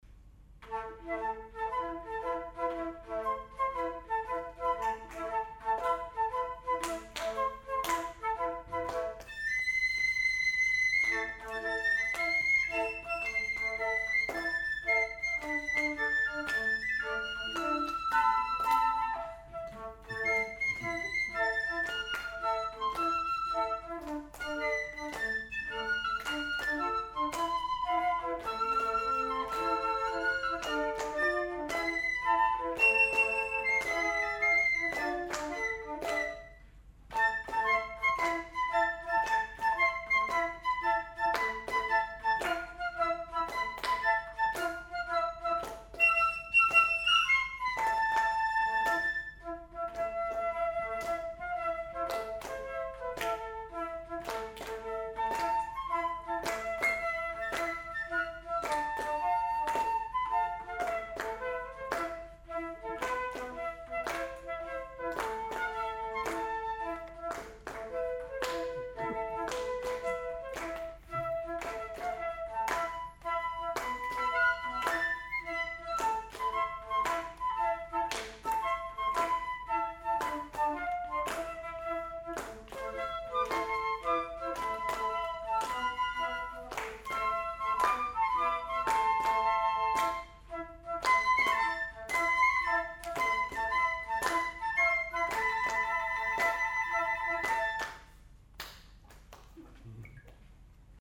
きのうはニューフィルの「アンサンブル大会」でした。
こちらで、その部分だけの録音が聴けますが、１分８秒あたりで、なんかそんな声が入っているようですからね（レコーダーは客席の後ろに立てておきました）。私のパートはアルトフルートの低音です。